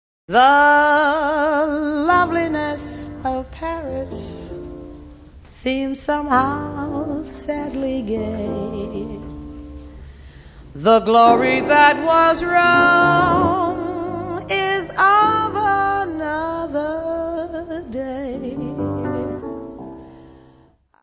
: stereo; 12 cm
Área:  Jazz / Blues